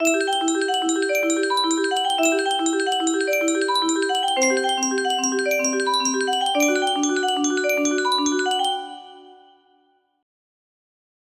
Intro only